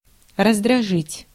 Ääntäminen
US : IPA : [ˈɪr.ə.ˌteɪt]